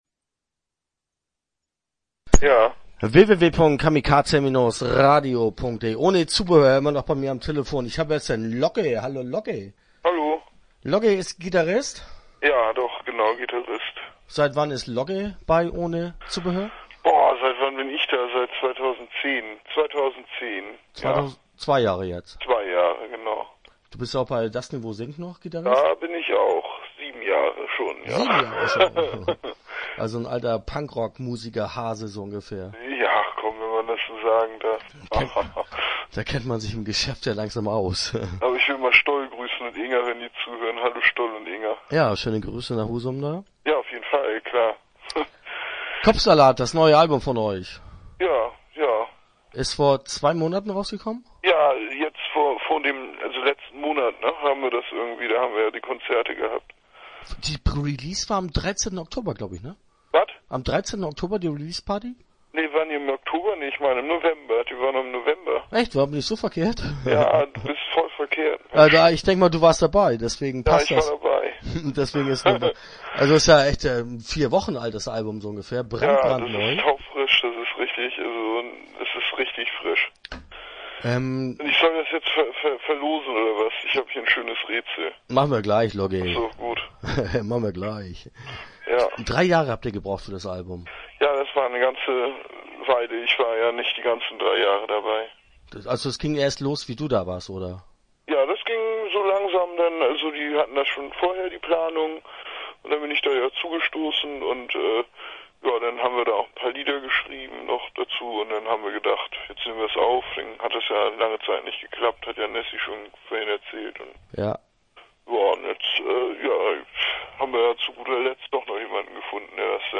Interview Teil 1 (8:03)